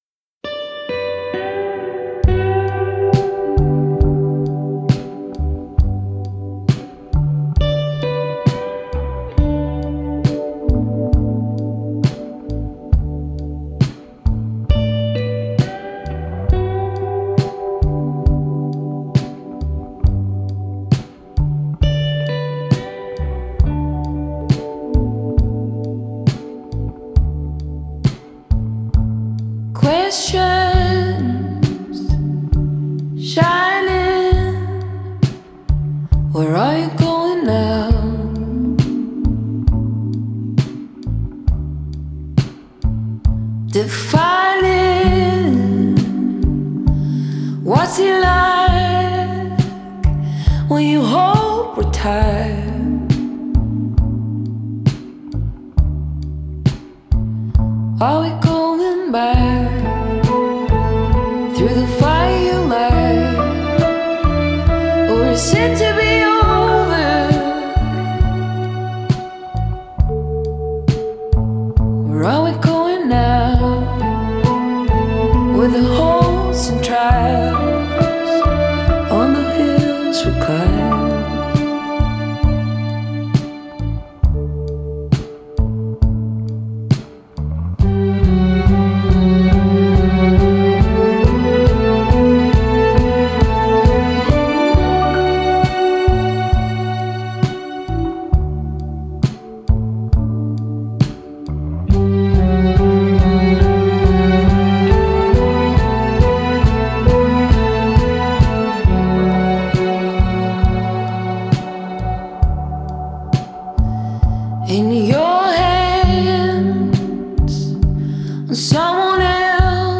Genre: Pop Folk